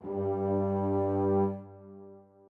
strings2_8.ogg